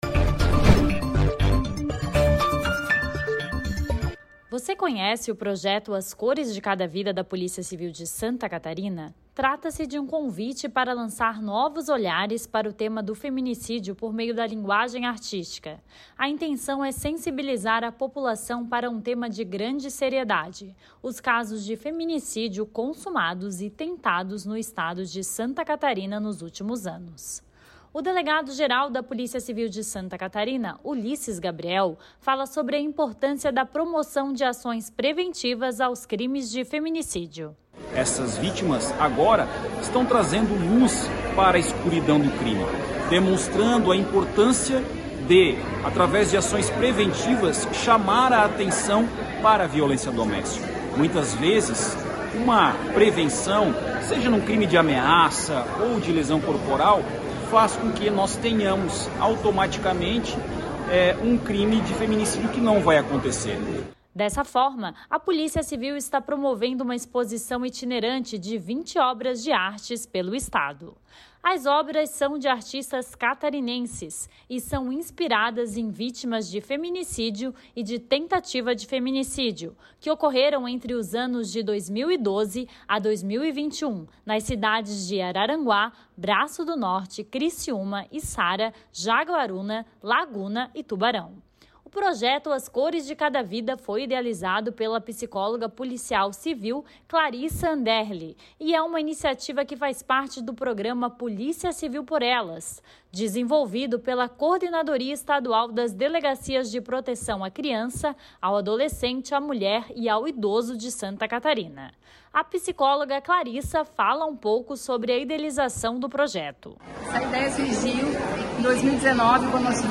Delegado-geral da Polícia Civil de Santa Catarina, Ulisses Gabriel.